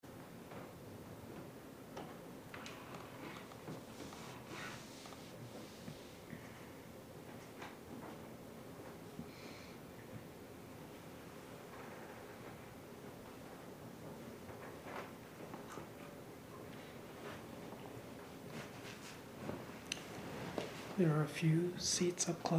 okay: to make it short but keep the metadata, I started an ftp download and interrupted it right away. hope this works. thanks! maybe I should add that the content is what I would cut away, but it has a voice piece on the quieter side.